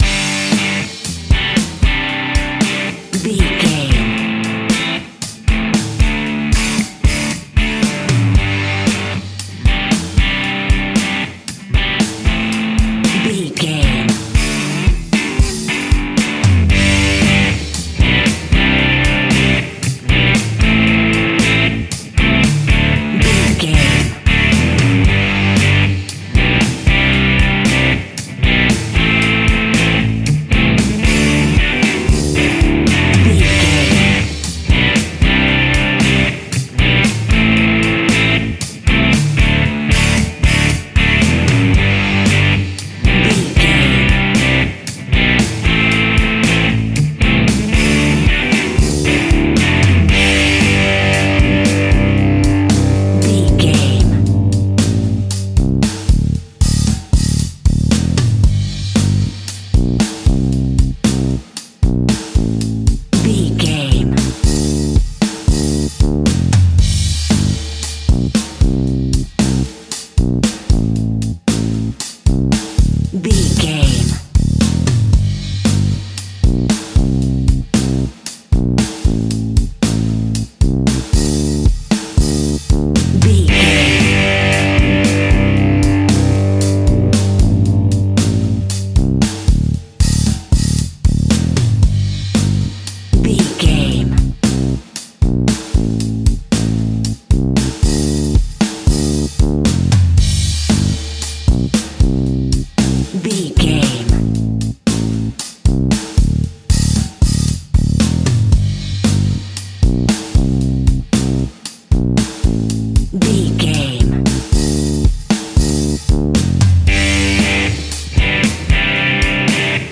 Ionian/Major
fun
energetic
uplifting
acoustic guitars
drums
bass guitar
electric guitar
piano
organ